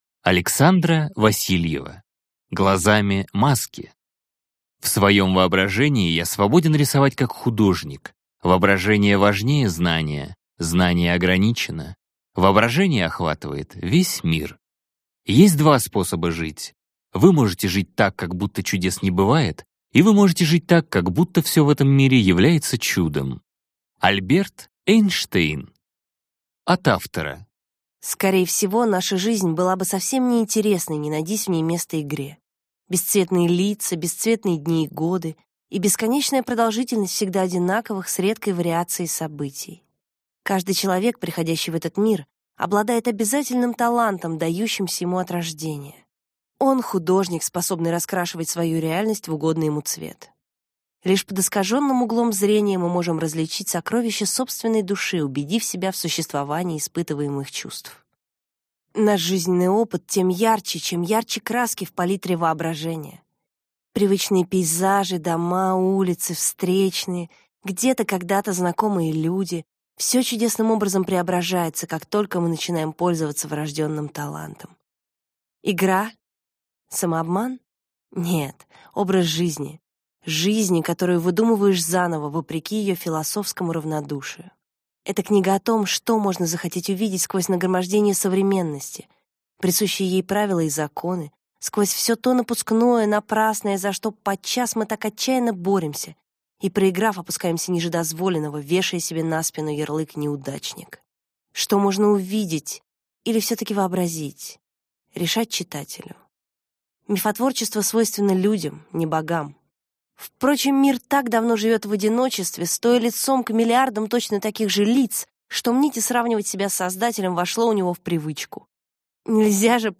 Аудиокнига Глазами маски | Библиотека аудиокниг
Aудиокнига Глазами маски Автор Александра Васильева Читает аудиокнигу Творческое Объединение «ТриТоны».